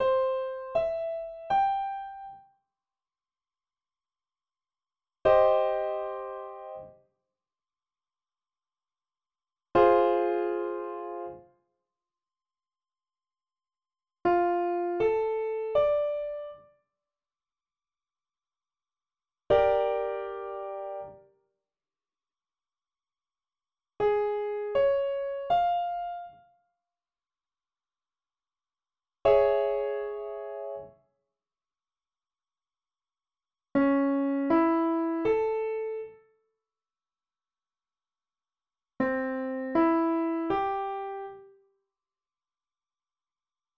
It consists of both types of triads, in all inversions, arpeggiated and in block form.